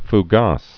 (f-gäs)